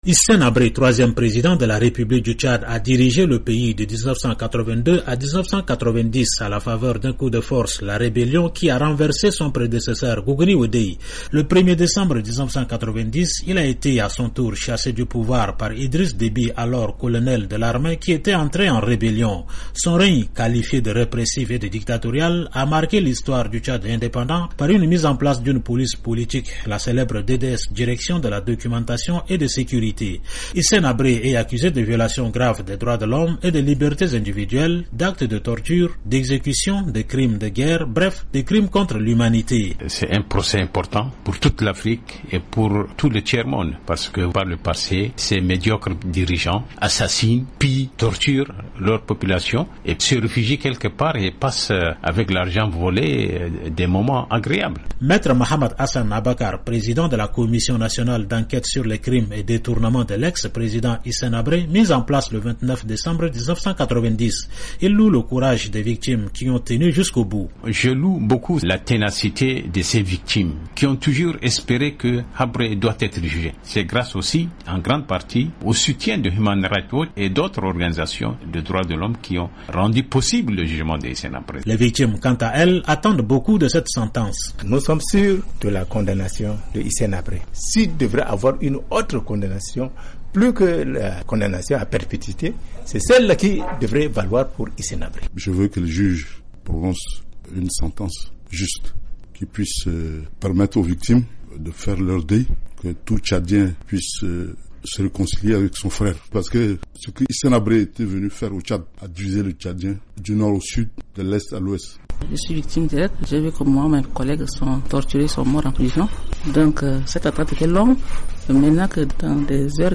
Reportages à Dakar et N’Djamena